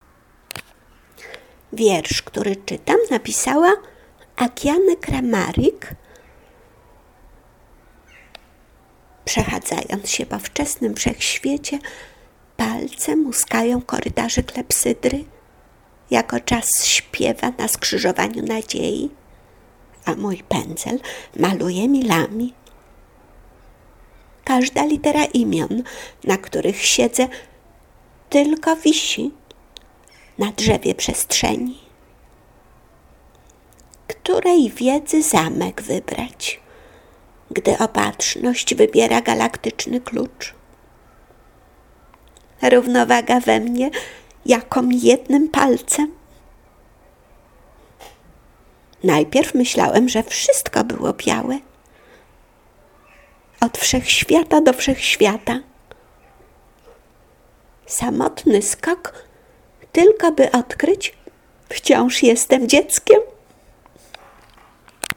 tekst, który czytam, napisała Akiane Kramarik, urodzona w 1994 r.